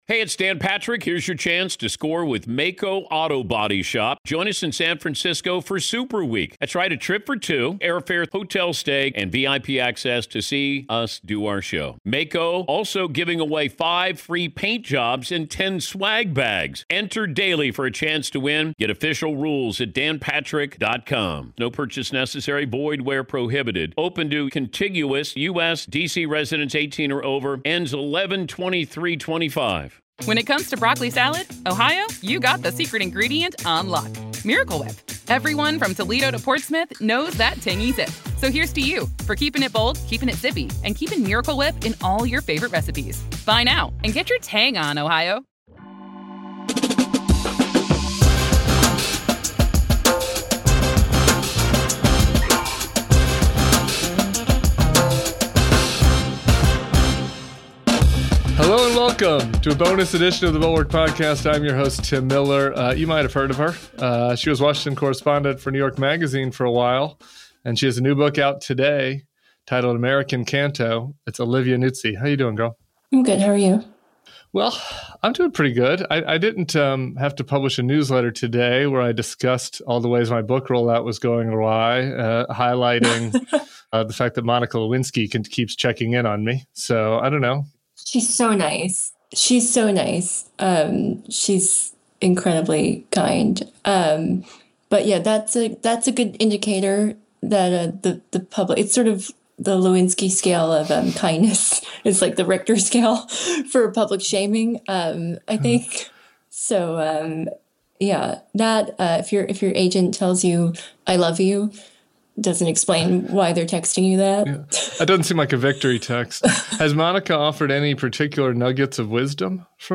In a sit down interview with Tim Miller, she talked about the ethical breaches that cost her her job, the conflict between her responsibilities as a reporter, the private relationship that blurred those boundaries, and the fear and isolation she experienced as the scandal unfolded.